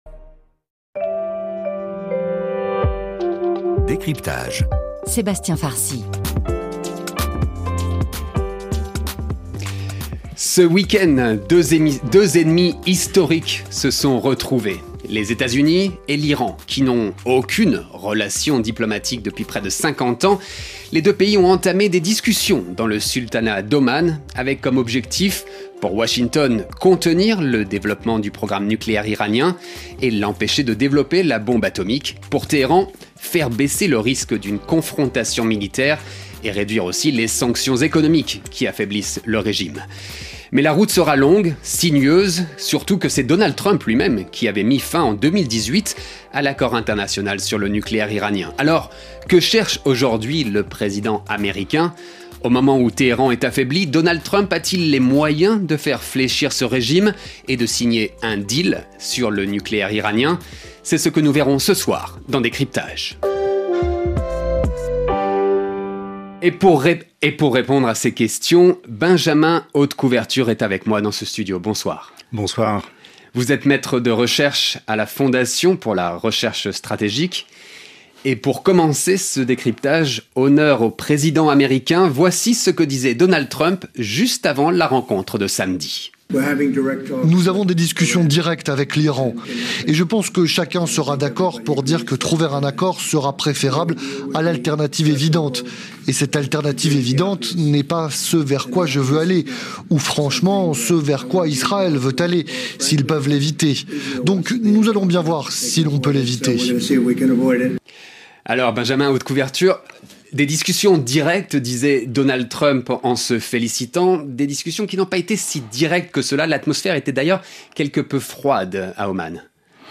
Le décryptage du fait d’actualité du jour avec un à trois spécialistes invités pour contextualiser, expliquer et commenter.